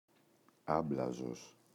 άμπλαζος [Ꞌablazos]